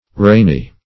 Rainy \Rain"y\ (r[=a]n"[y^]), a. [AS. regenig.]